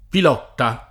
[ pil 0 tta ]